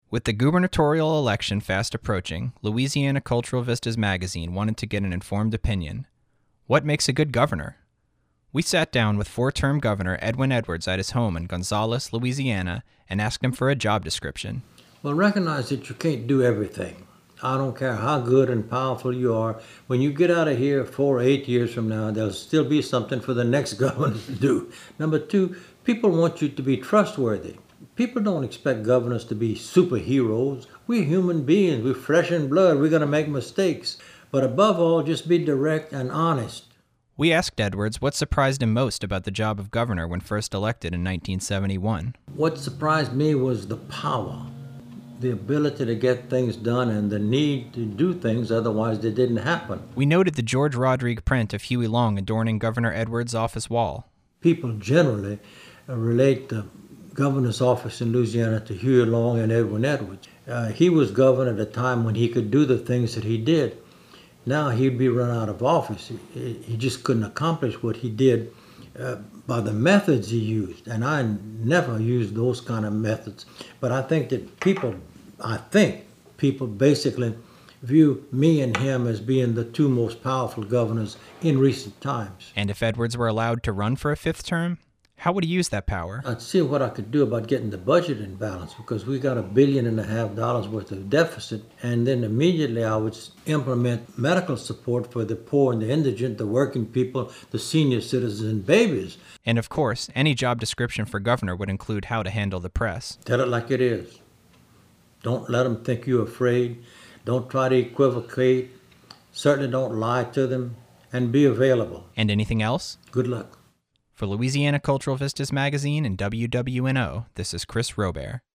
Edwin Edwards in conversation with Louisiana Cultural Vistas